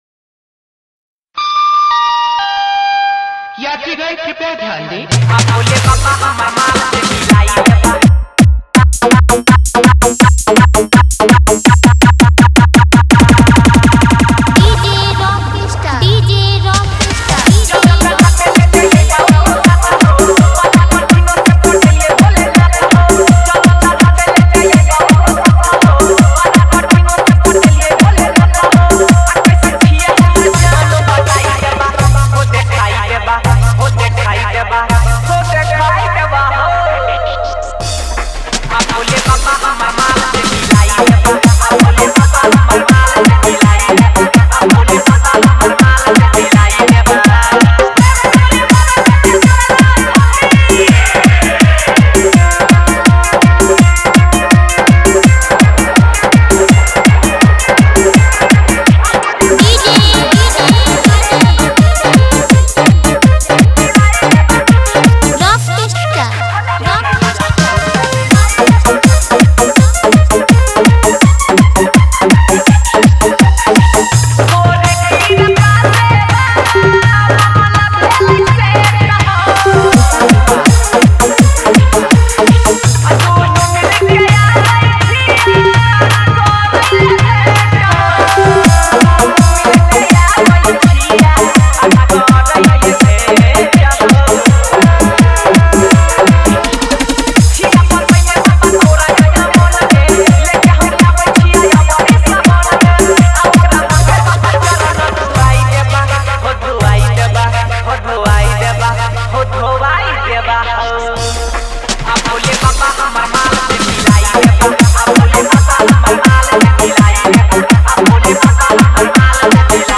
Category:  Bol Bam 2021 Dj Remix Songs